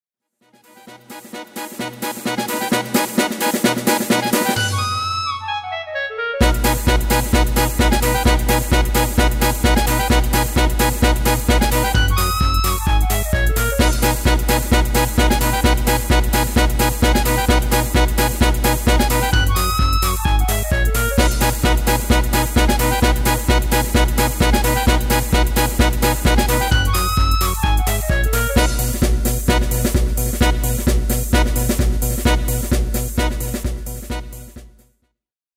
Demo/Koop midifile
Taal uitvoering: Instrumentaal
Genre: Jazz / Big Band
Originele song is instrumentaal